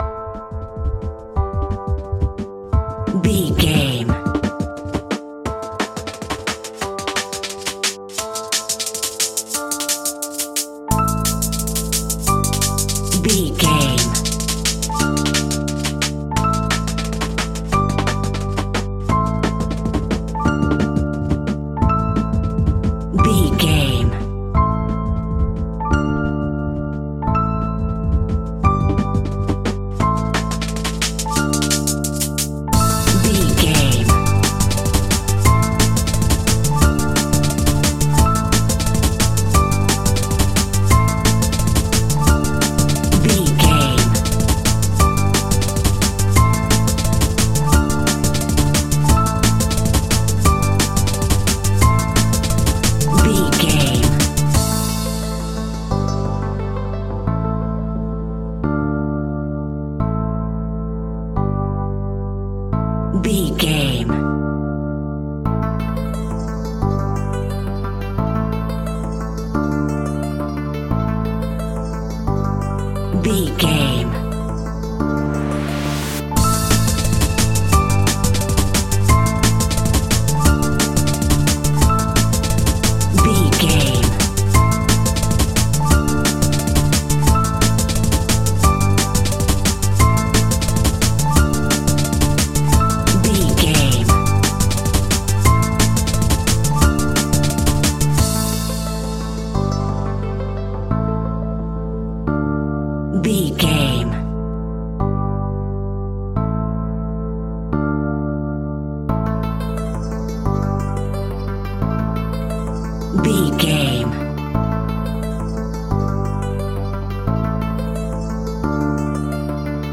A great piece of royalty free music
Aeolian/Minor
WHAT’S THE TEMPO OF THE CLIP?
Fast
aggressive
dark
driving
energetic
groovy
drum machine
synthesiser
electronic
sub bass
synth leads
synth bass